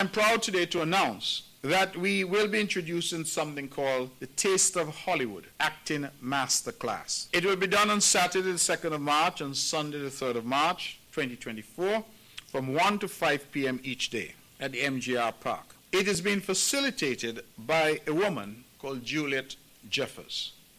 That was Premier of Nevis, Hon Mark Brantley.